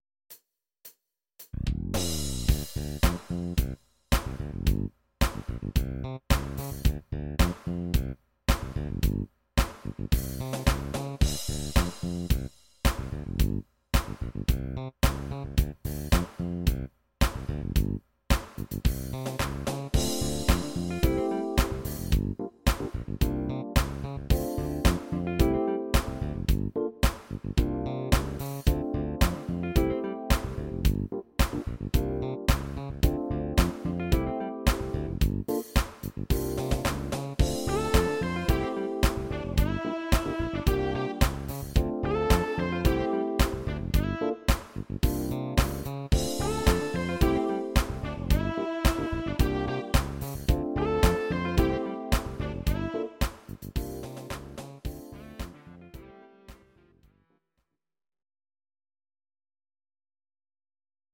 Please note: no vocals and no karaoke included.
Your-Mix: Disco (724)